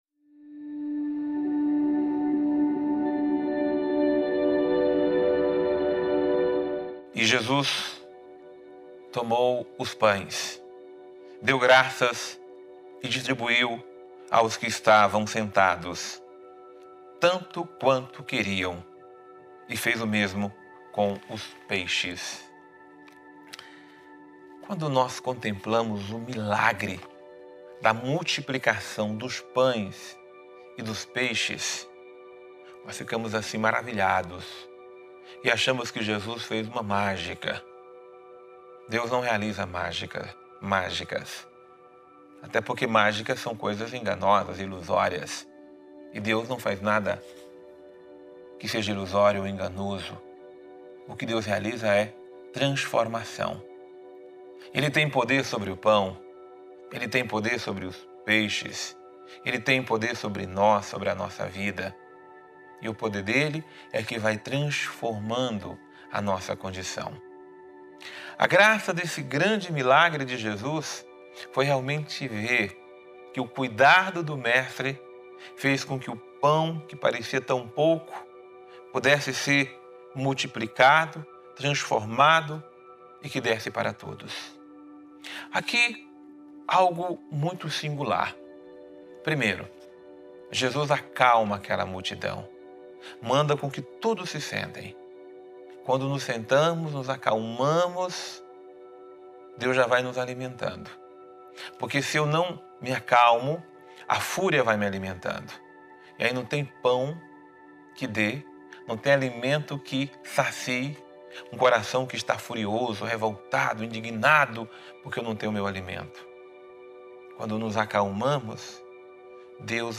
Homilia Diária